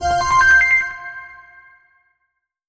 Stat-Increase.wav